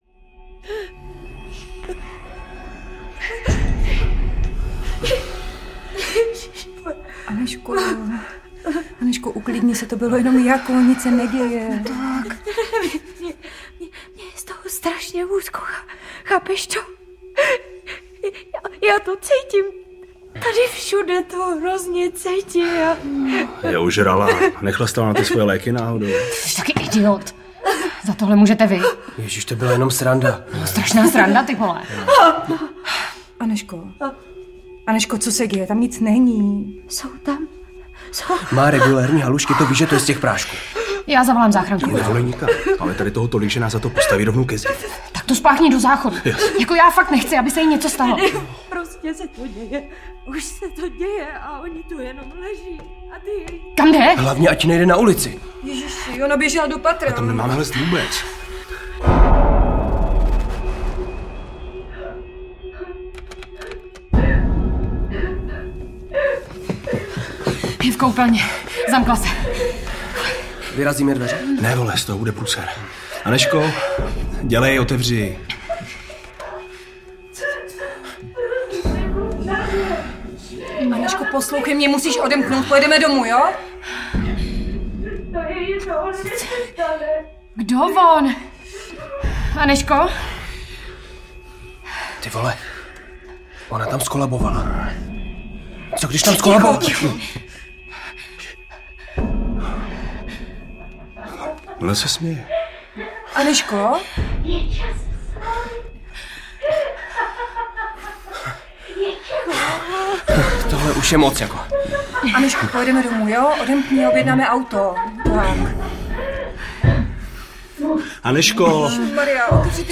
Šum audiokniha
Ukázka z knihy